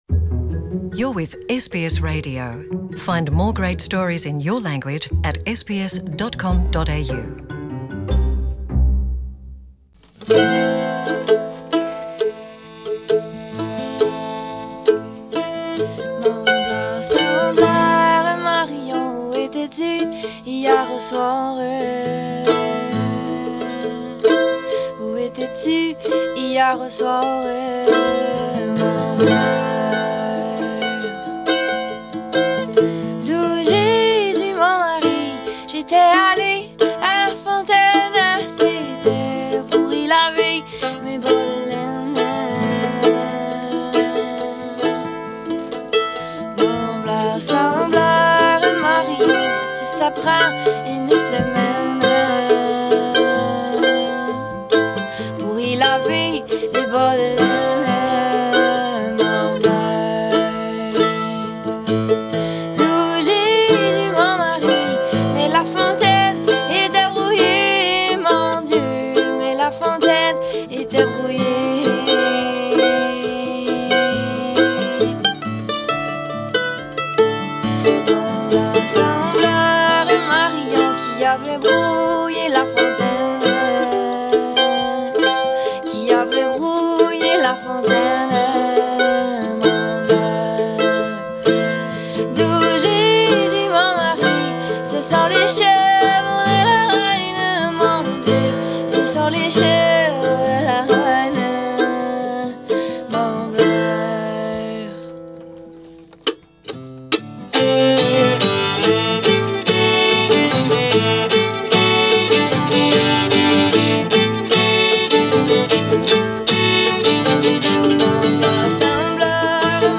ce groupe canadien
guitars, vocals
electric bass, vocals
fiddle, lead vocals
mandolin, banjo, lapsteel, foot taping, vocals
piano, lead vocals) en live dans le studio